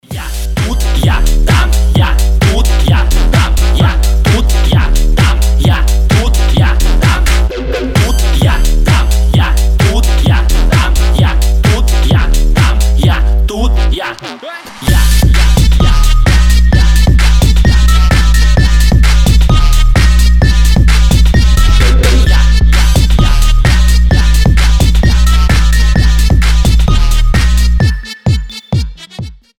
• Качество: 320, Stereo
мощные басы
Trap
взрывные
Trapstep